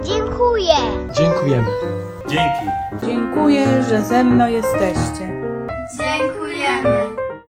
–  to fragment filmu, który powstał dzięki kreatywności i zaangażowaniu ełczan.
Mieszkańcy Ełku najpierw pisali na kartce, komu i za co chcą podziękować, a następnie, trzymając ją w ręku, nagrywali filmiki, w którym pojawiało się słowo „dziękuję”.